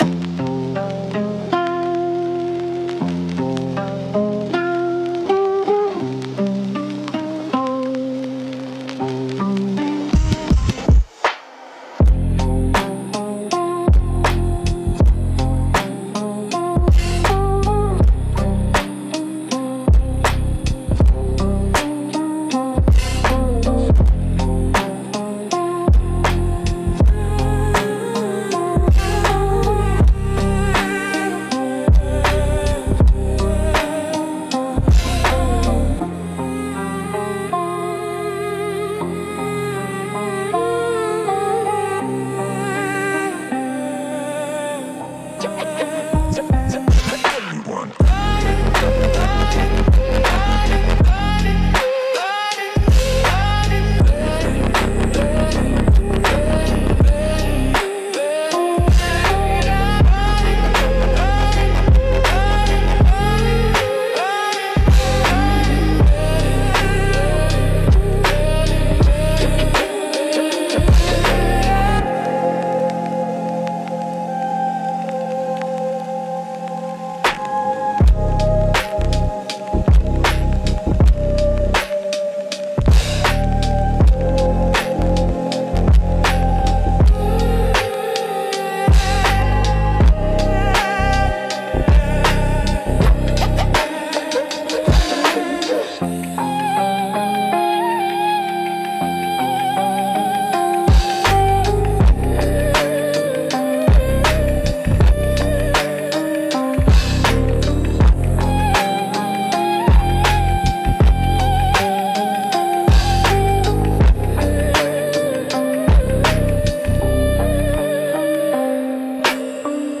Instrumental - Drifting Through the Grid